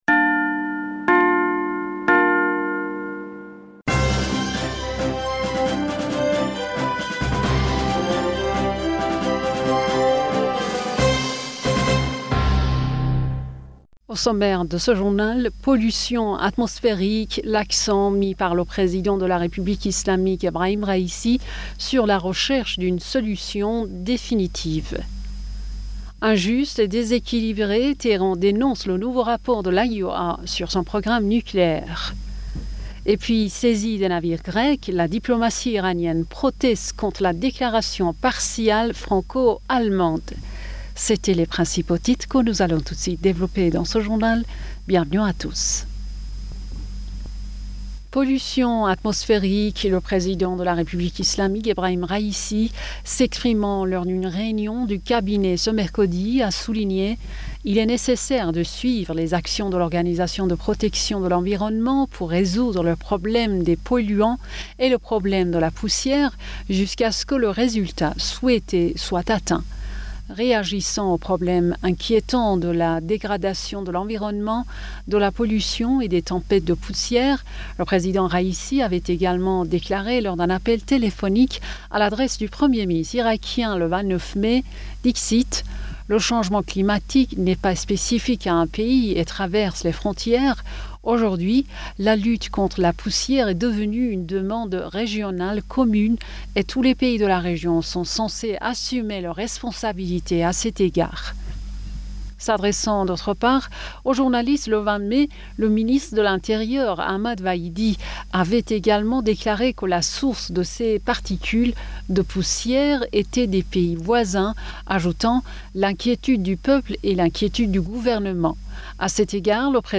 Bulletin d'information Du 01 Juin